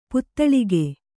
♪ puttaḷige